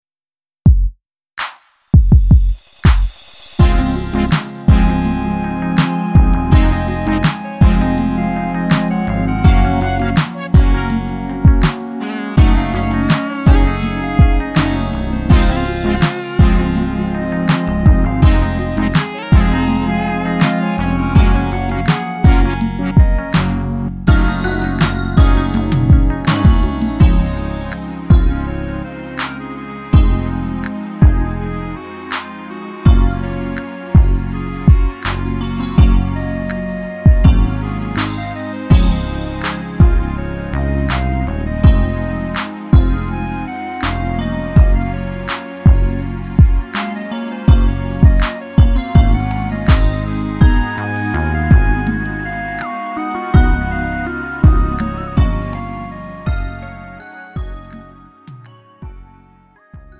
음정 -1키 4:07
장르 가요 구분